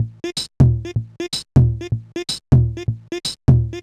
cch_jack_percussion_loop_ditdit_125.wav